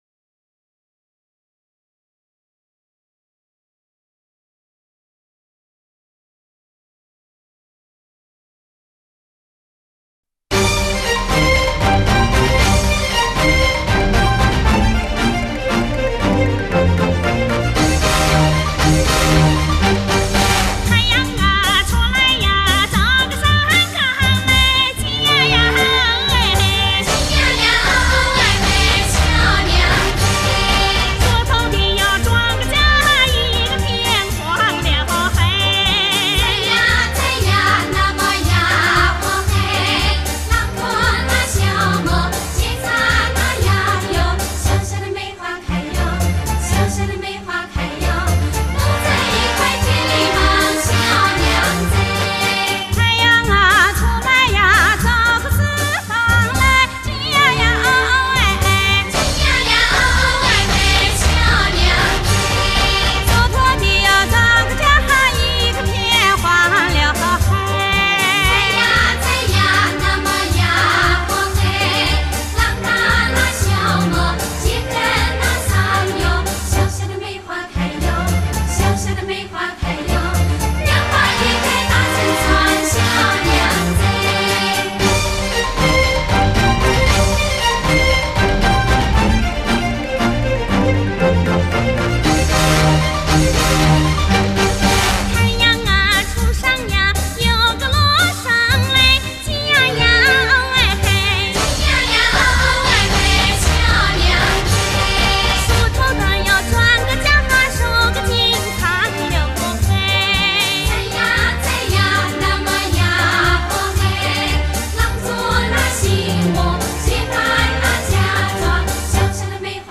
民歌